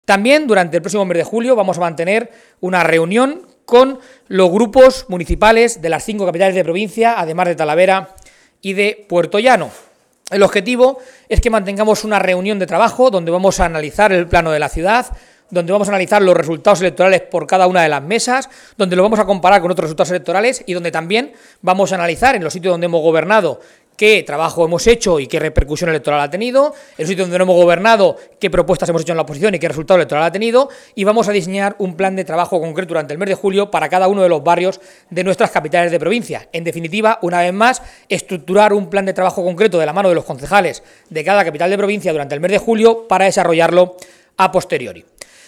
Así se ha expresado Núñez ante los medios en una comparecencia previa al Comité de Dirección del Partido Popular de Castilla-La Mancha que ha tenido lugar en Toledo.